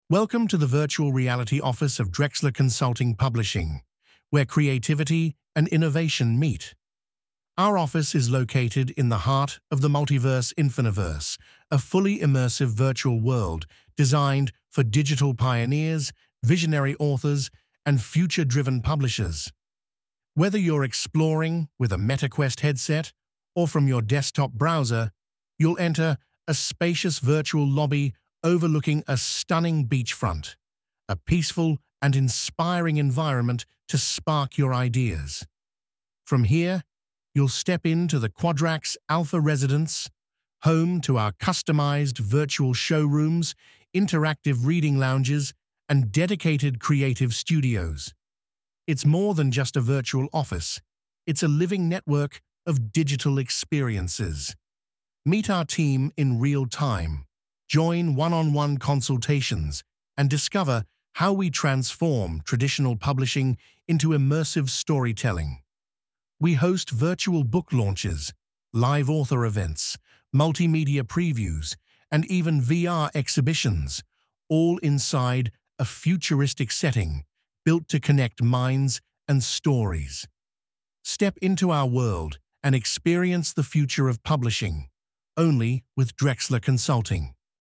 Barrier-free audio guide introducing the Drexler Consulting Virtual Reality Office in the Multiverse Infiniverse.